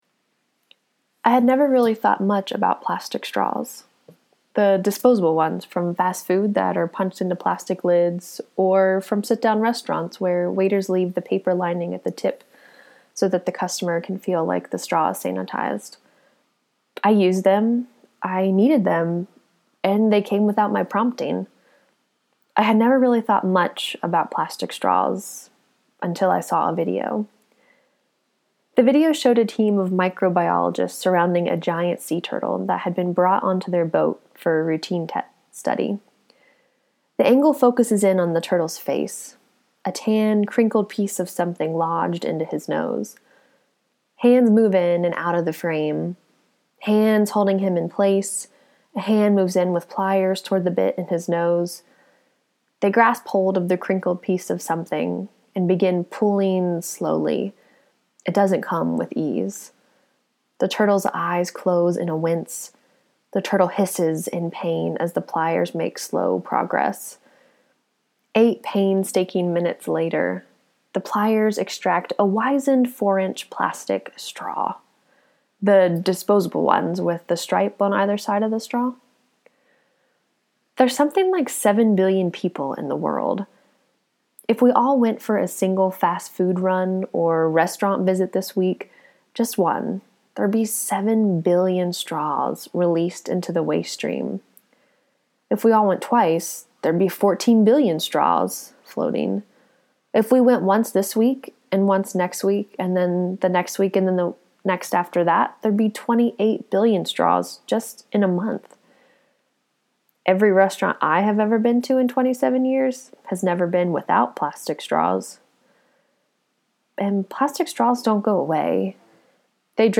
For this blog post, we are publishing two audio recordings in the style of NPR’s “This I Believe.”